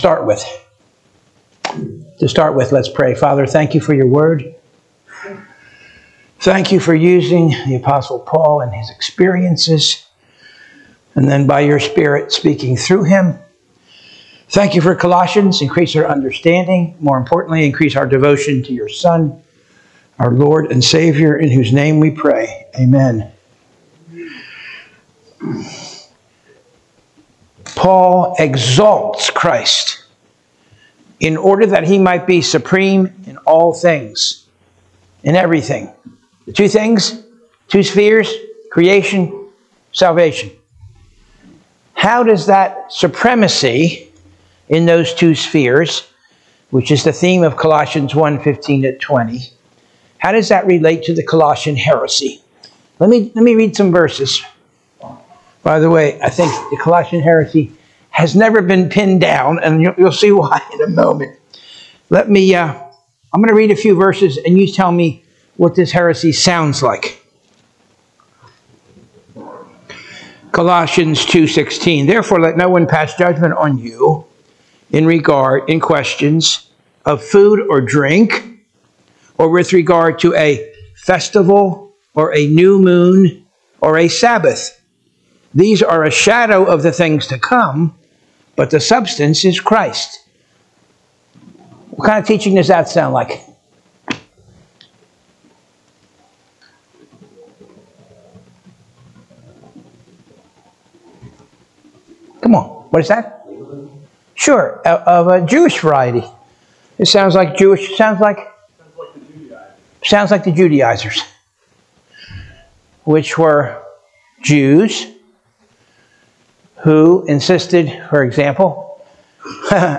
Sunday School - Covenant of Grace Church
Sunday-School.mp3